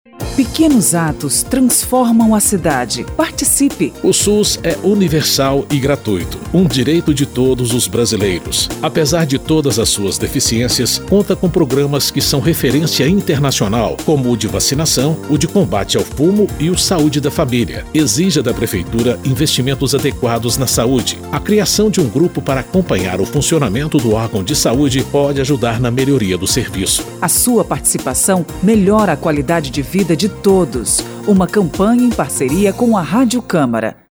São 7 spots de 30 segundos sobre saúde, transporte, educação e segurança, destacando o papel de cada um – prefeito, vereadores e cidadãos – na melhoria da vida de todos.
spot-pequenos-atos-5.mp3